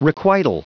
Prononciation du mot requital en anglais (fichier audio)
Prononciation du mot : requital